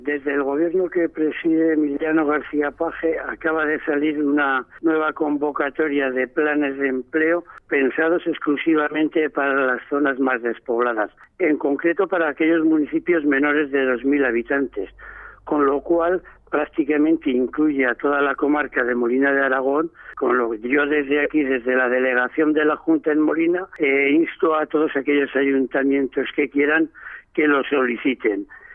El delegado de la Junta en la Comarca de Molina de Aragón, Alfredo Barra, habla del Plan de Empleo para zonas ITI impulsado por el Gobierno regional.